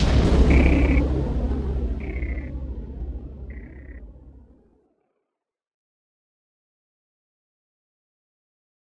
Processed Hits 22.wav